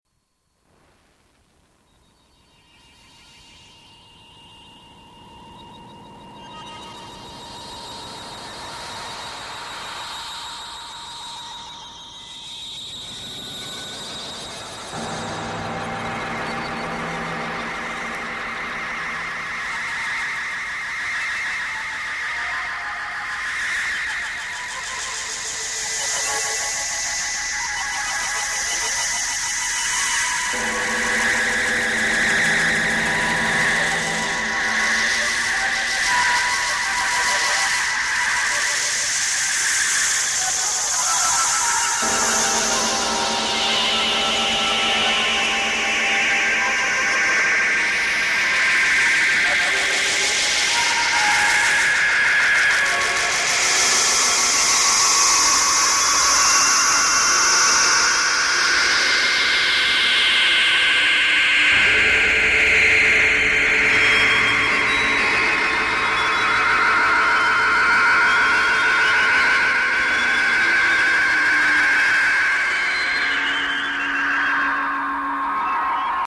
Compilations (electronics, w/wo video)
Audio sample-stereo mix from quad (.mp3)
Much use is made of space. Sounds exist in a four-dimensional continuum.
The organization of the composition is highly dependent upon the spacial effects of similar musical materials.
The work is divided into two major sections occurring halfway through the composition, signaled by a short period of silence and no visuals (black).